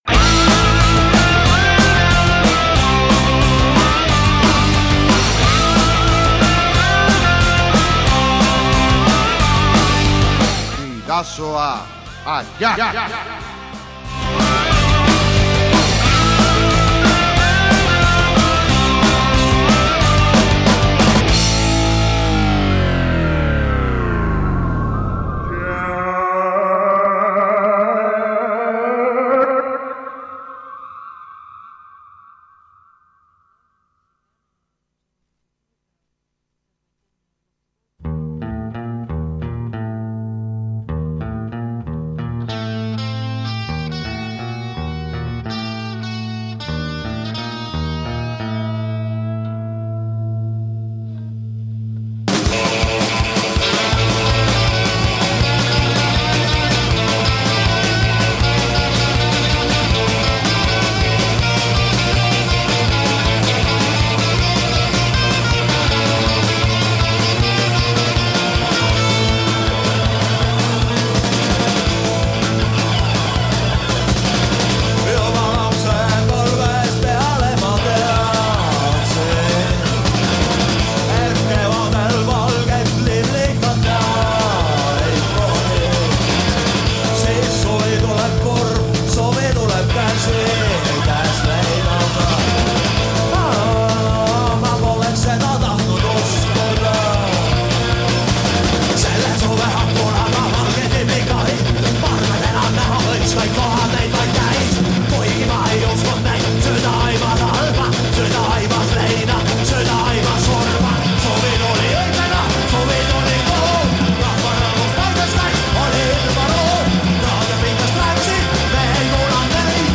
BIDASOA ATTAK!- Estoniako Punka